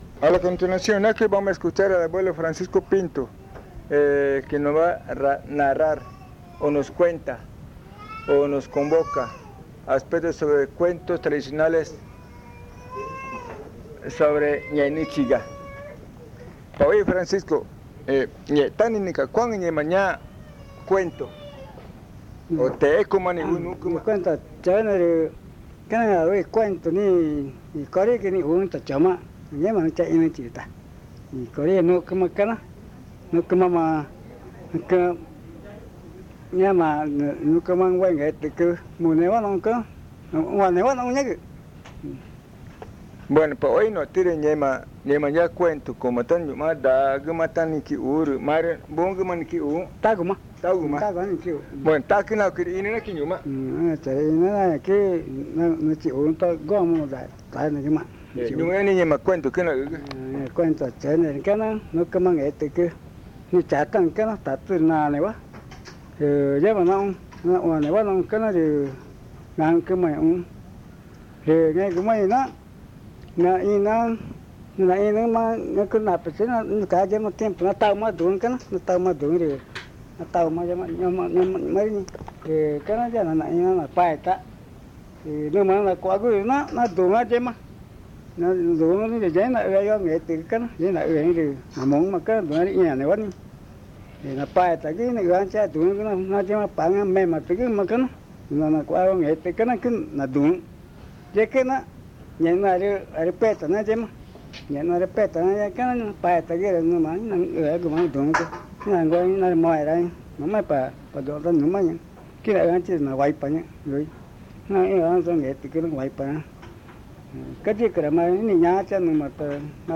San Juan del Socó, río Loretoyacu, Amazonas (Colombia)
El abuelo narra en Magütá la historia de un jóven que, al bajar en canoa por el río Amazonas, escuchó música y creyó que era una fiesta. En realidad era la fiesta de la pava hedionda, donde vio muchas mujeres bonitas. Se quedó allí, pero al despertar estaba en el monte amarrado a un palo.